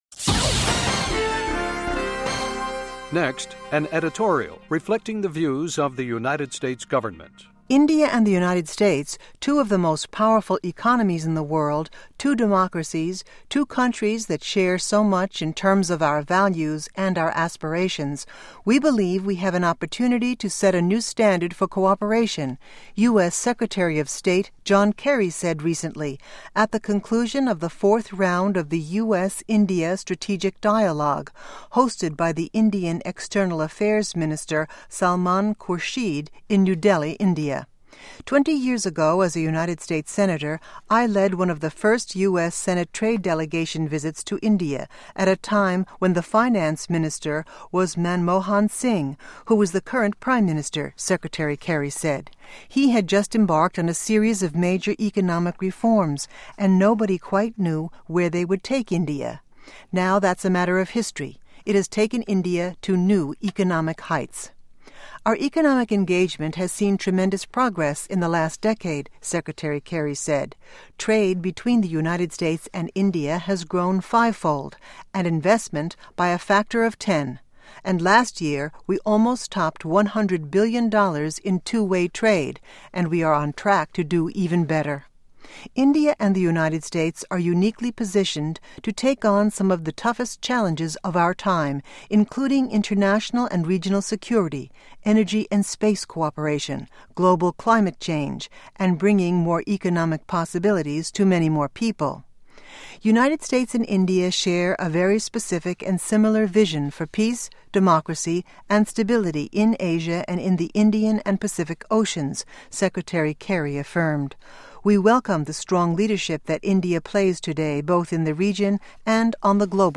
U.S. Secretary of State John Kerry comments at the conclusion of the fourth round of the U.S.-India Strategic Dialogue in New Delhi, India.